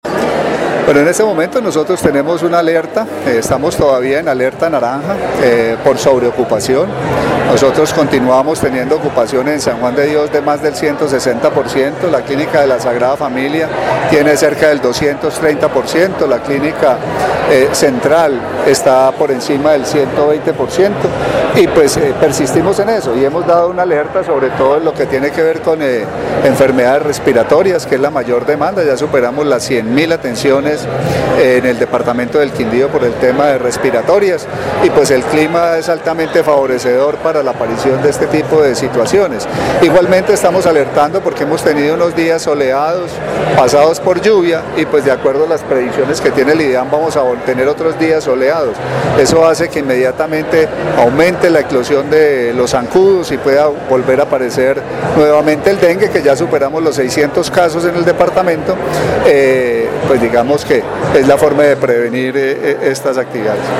Secretario de salud del Quindío